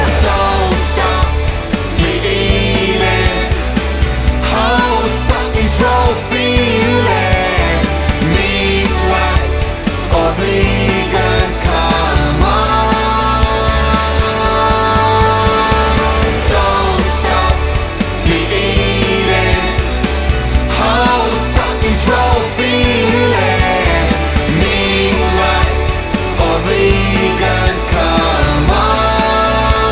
• Comedy